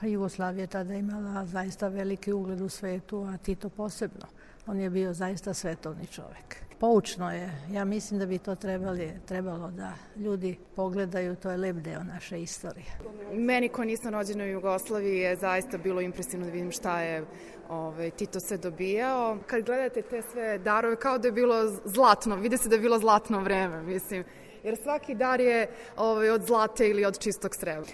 Posetioci o izložbi